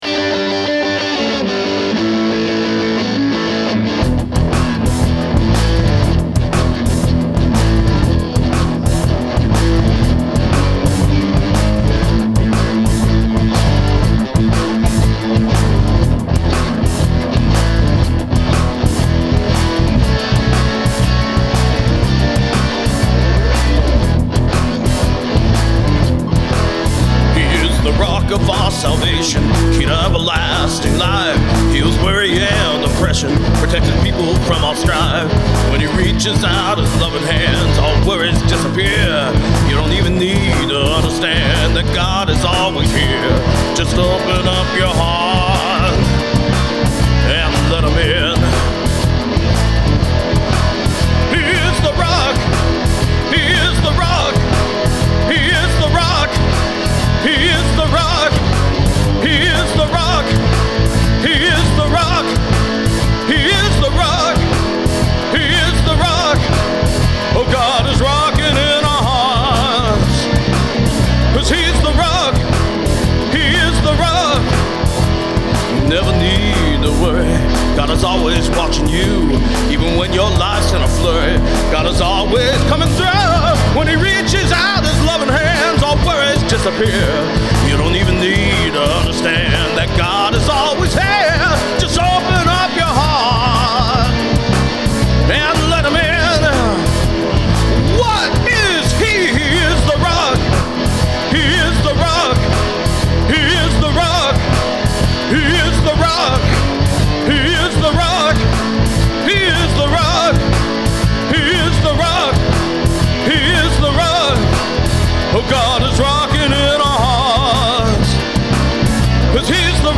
I wrote this song several years ago as a fun, foot-stompin’, rockin’ blues crowd-pleaser to close out church services, and like my previous song, The Way The Truth The Life, finally got around to recording it – actually I attempted to record it a lot over the years, but just couldn’t get a good vibe with it.
But last year, I decided to have some fun with it, and do kind of an “Elvis” voice, and that’s when it changed the whole song and got me over the hump.
I just used my Fender American Deluxe Strat in this one for all guitar parts, and ran it through my Roland Cube 30 set to “Classic British Stack” so I could get that mid-rangy Marshall tone. I cheated a little with the lead and added a software overdrive plug-in to give the lead even more bite and sustain.